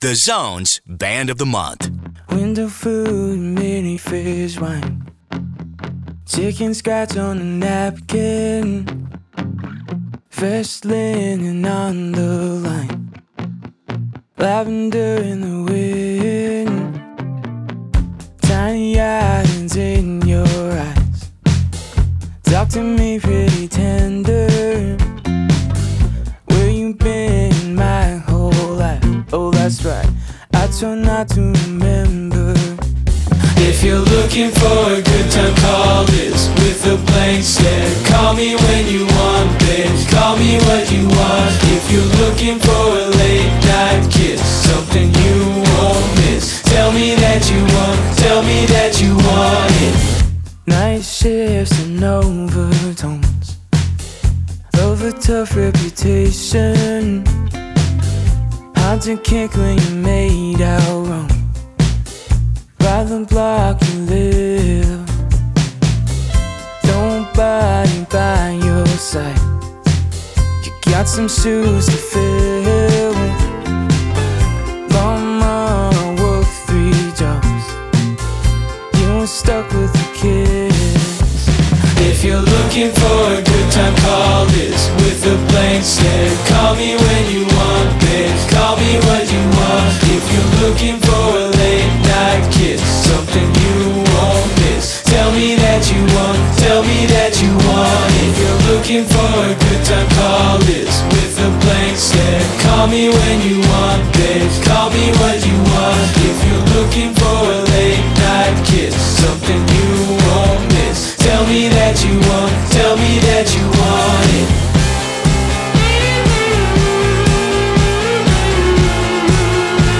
Vocals/Guitar
alternative rock duo from Vancouver Island